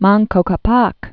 (mängkō kä-päk) also Manco In·ca Yu·pan·qui (ĭngkə y-pängkē) Died 1544.